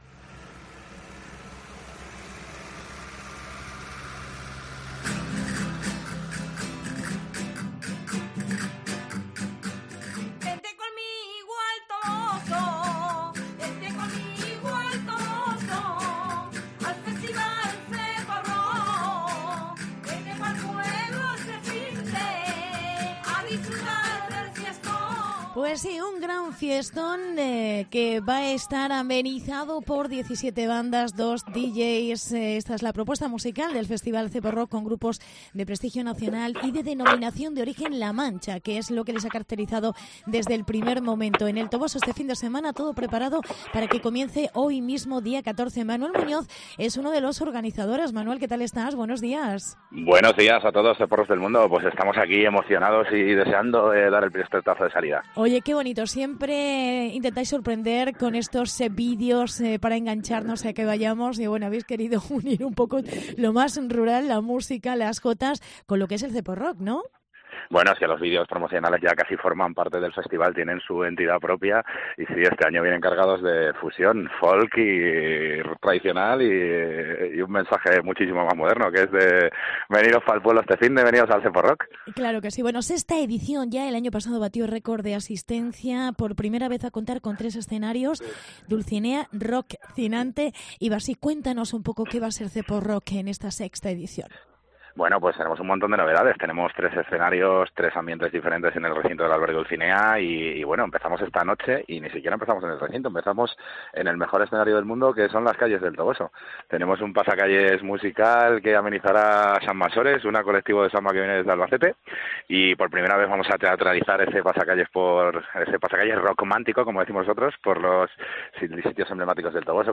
ZEPOROCK 2019. Entrevista